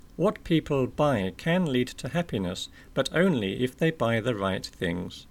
DICTATION 5